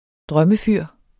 Udtale [ -ˌfyɐ̯ˀ ]